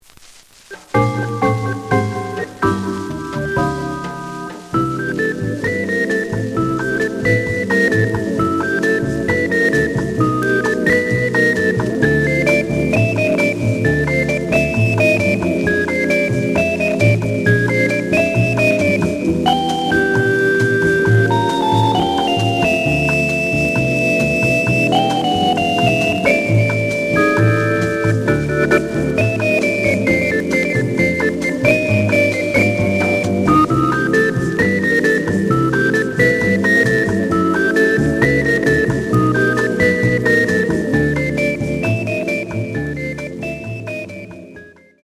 The audio sample is recorded from the actual item.
●Genre: Latin Jazz
Looks good, but some noise on beginning of both sides.